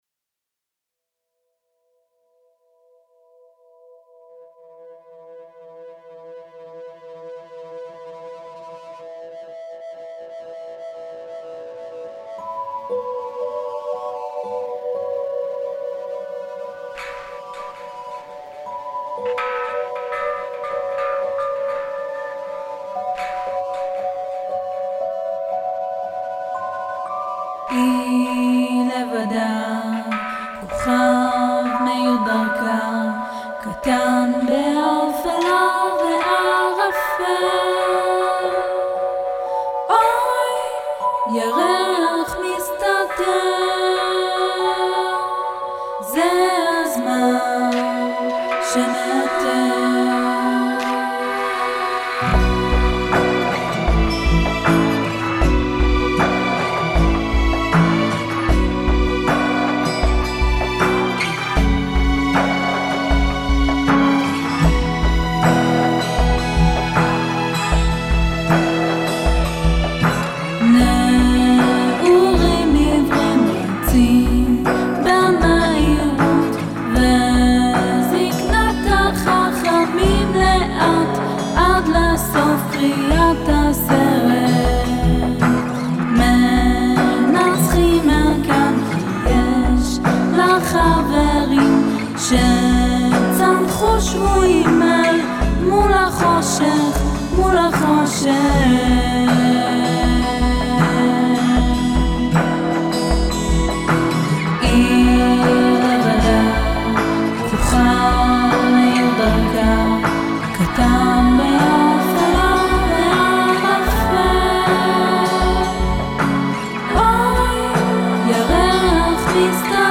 מילים,לחן ושירה: אני
יש כמה שניות שלא שומעים כלום...